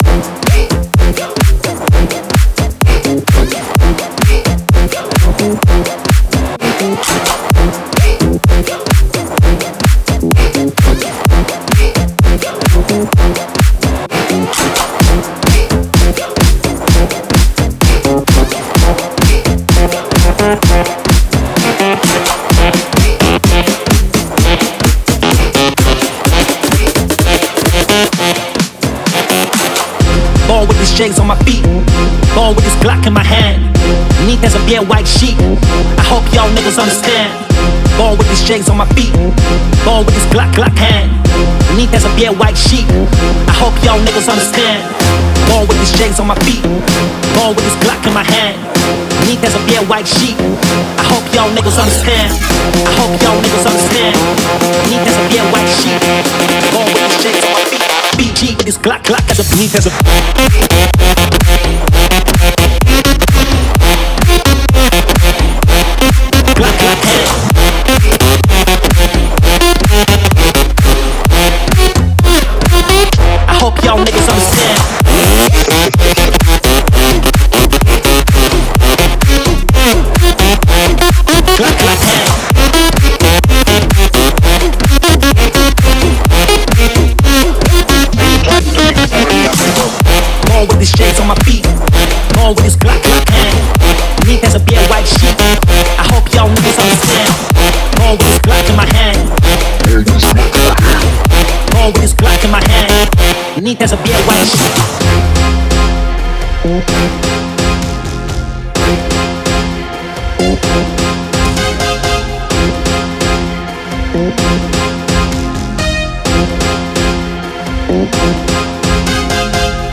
Key: G# Minor🥁 BPM: 128
Genre: Future Bounce